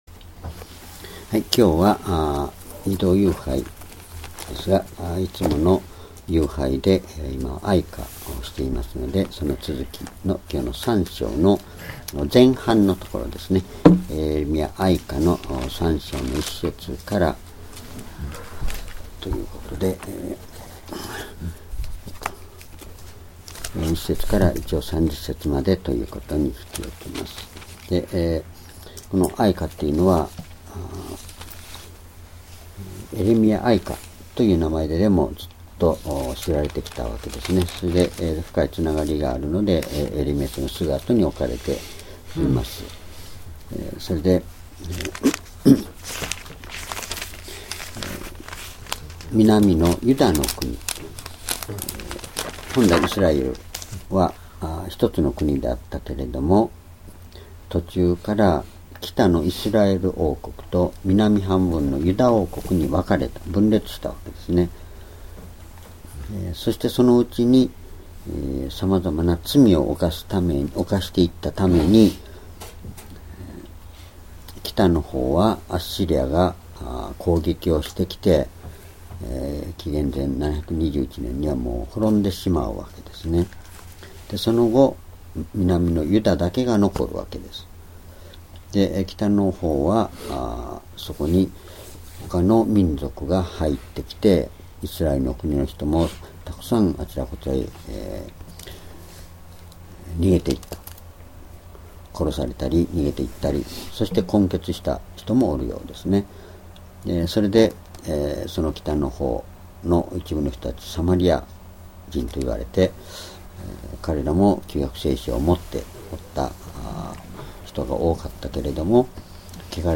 主日礼拝日時 2018年3月27日 移動夕拝 聖書講話箇所 「絶望的状況における信仰と希望と愛」 哀歌3章1節～30節 ※視聴できない場合は をクリックしてください。